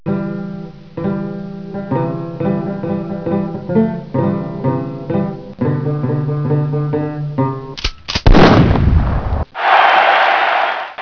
Banjo player getting shot.
banjo.wav